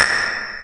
PERC - ANTI.wav